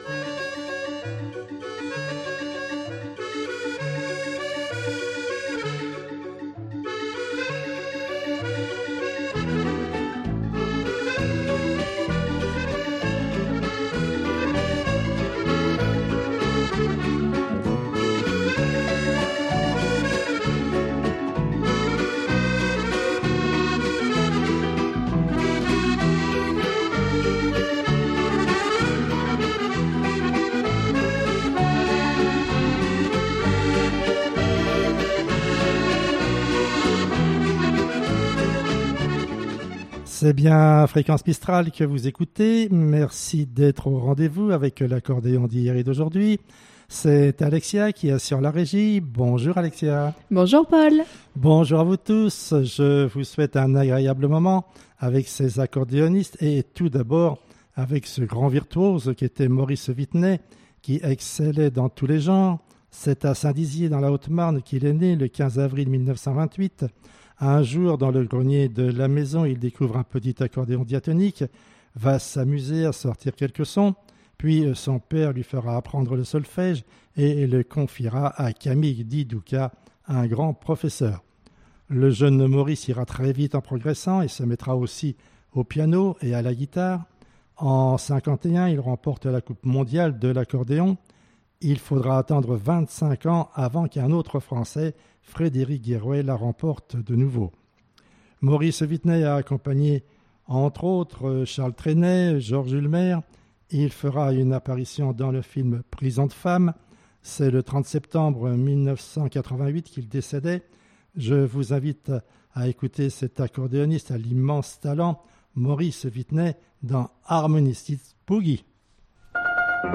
Accordéon